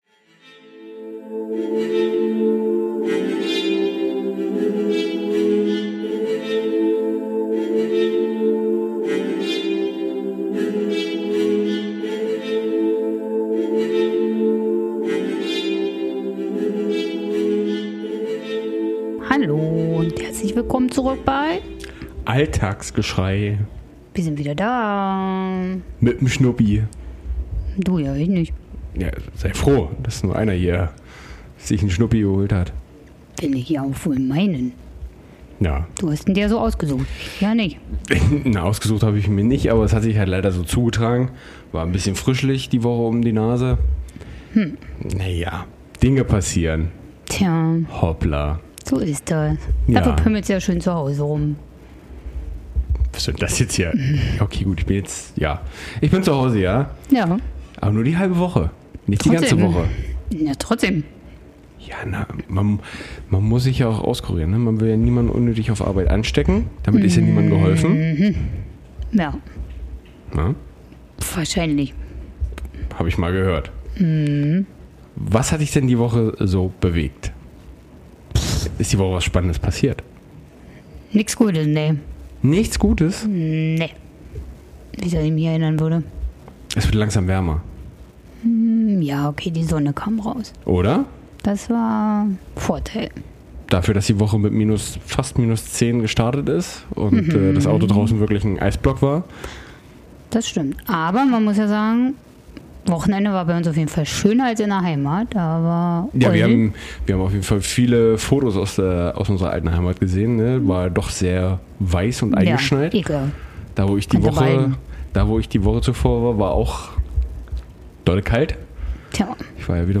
Heute beginnt der Podcast mit kleinen Stimmungsschwankungen, artet aus in völliger Euphorie und Meinungsäußerung und wird am Ende doch noch ganz nett. Hört rein wenn es heute etwas emotionaler wird und lasst euch von unseren Fragen und Songs überraschen.